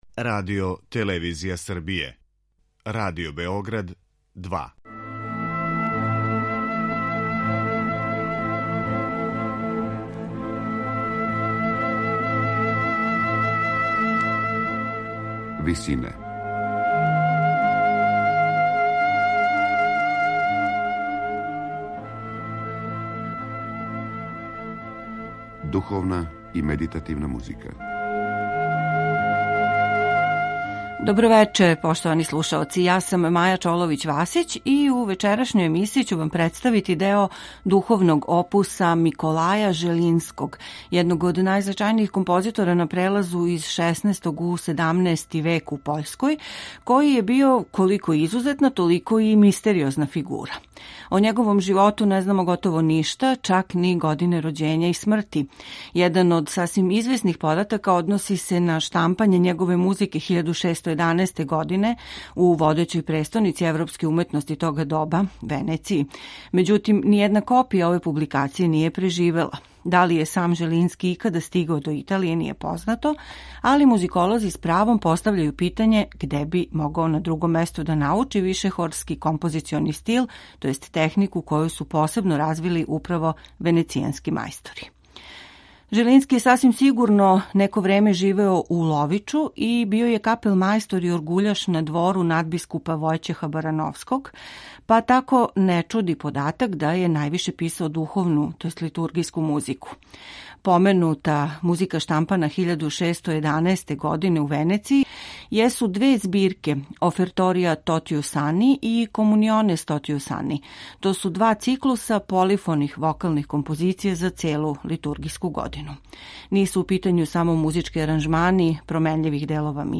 сопран